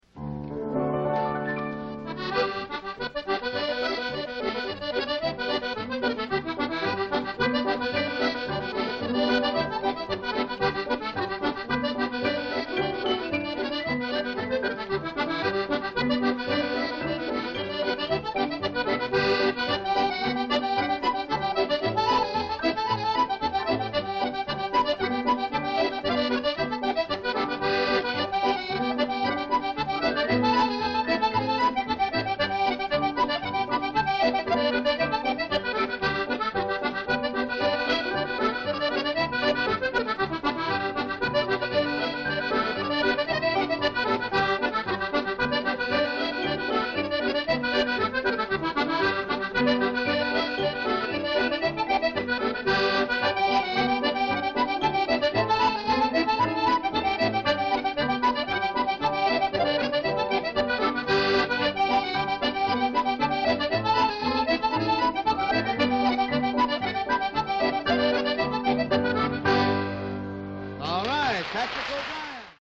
born 10 February Mp3 Sound Effect Paddy O’Brien, born 10 February 1922, was an influential Irish button accordion player and composer from Newtown near Nenagh in Ireland.